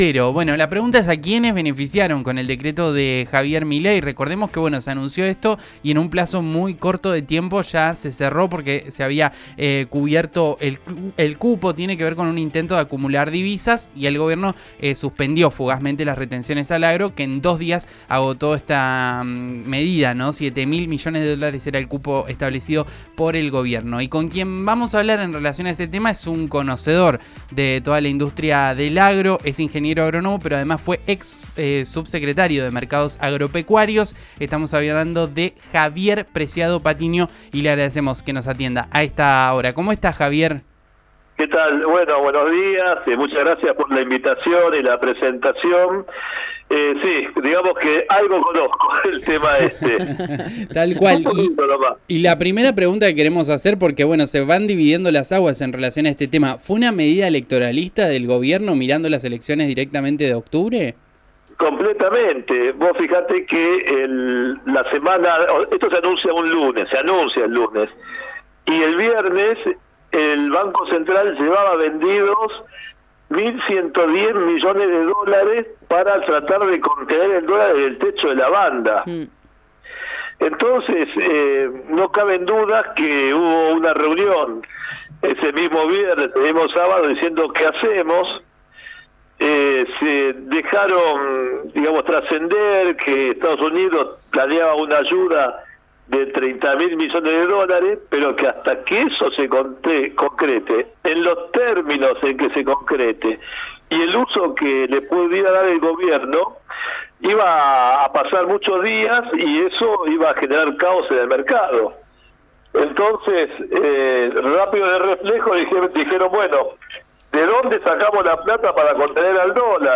Escuchá la entrevista en RN RADIO.